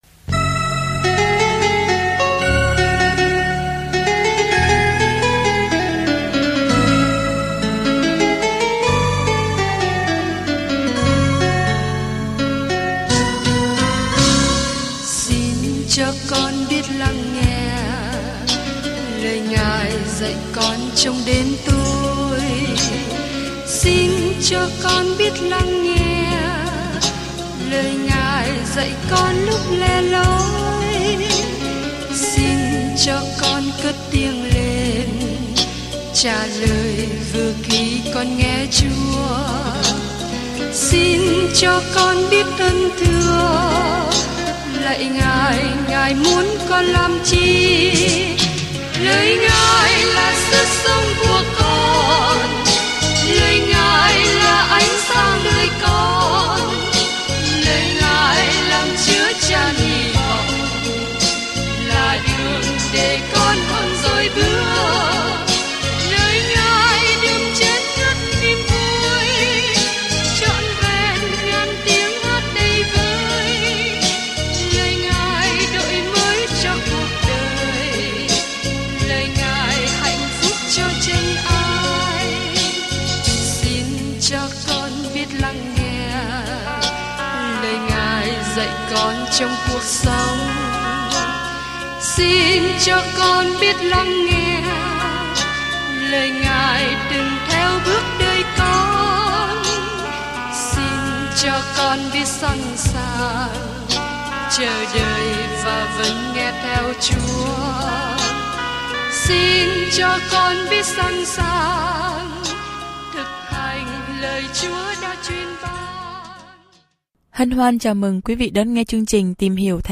Kinh Thánh Nê-hê-mi 9 Nê-hê-mi 10 Ngày 10 Bắt đầu Kế hoạch này Ngày 12 Thông tin về Kế hoạch Khi Israel trở về vùng đất của họ, Jerusalem đang trong tình trạng tồi tệ; một người đàn ông bình thường, Nê-hê-mi, đã xây lại bức tường xung quanh thành phố trong cuốn sách Lịch sử cuối cùng này. Du lịch hàng ngày qua Nê-hê-mi khi bạn nghe nghiên cứu âm thanh và đọc những câu chọn lọc từ lời Chúa.